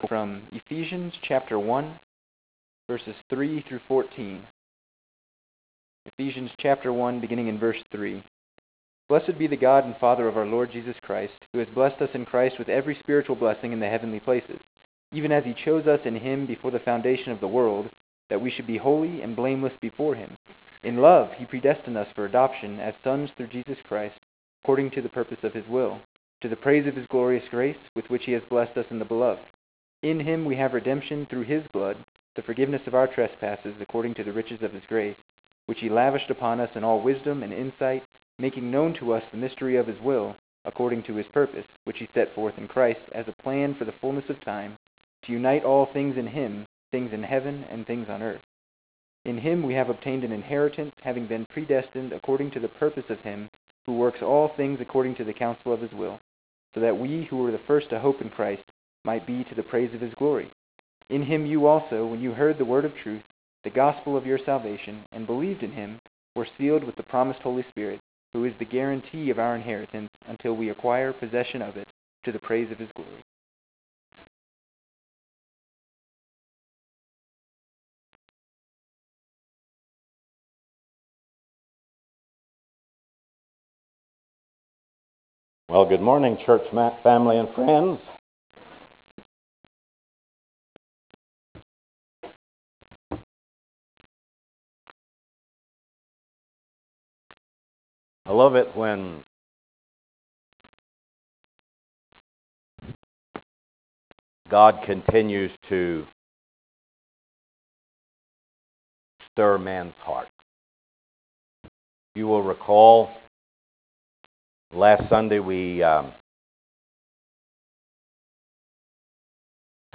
Sept 14 2014 AM sermon